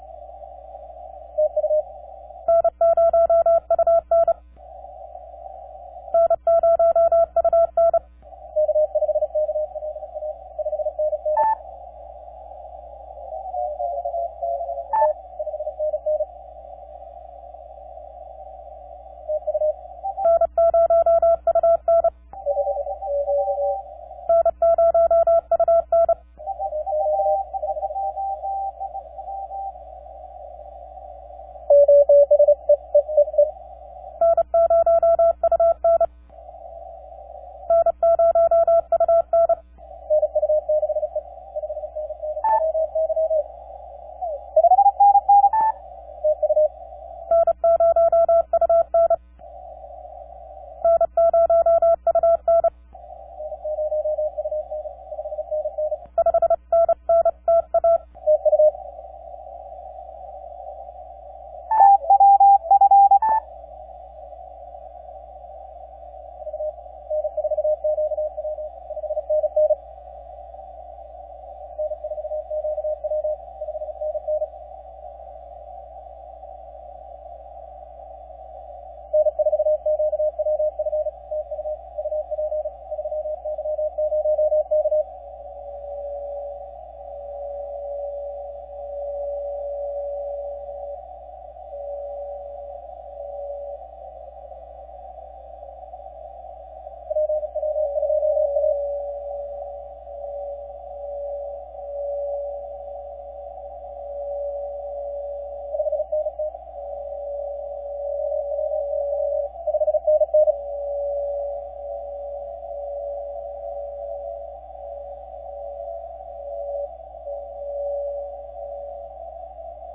3Y0K 10 Meters CW
Here’s the 8 element GXP antenna doing its thing short path to Bouvet!